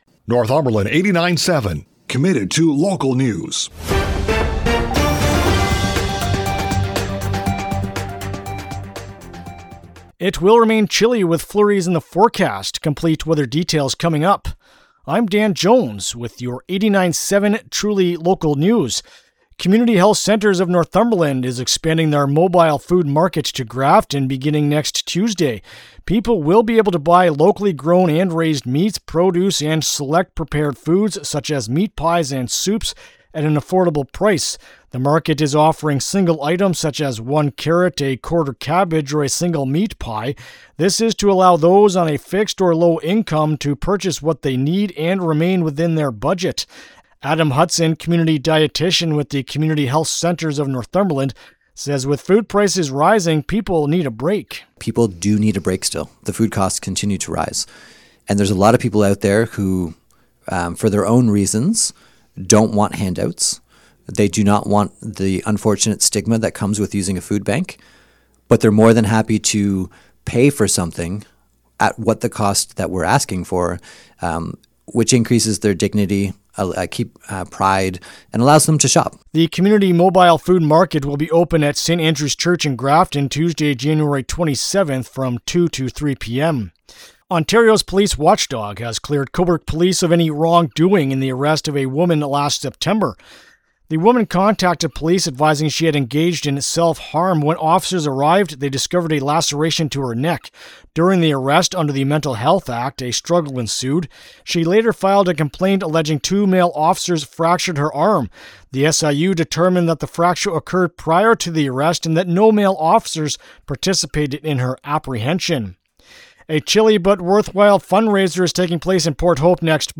newscast
CFWN-Cobourg-ON.-Thursday-Jan.-22-Morning-News.mp3